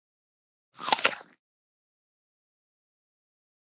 SnglAppleBite PE677803
DINING - KITCHENS & EATING APPLE: INT: Single bite into an apple.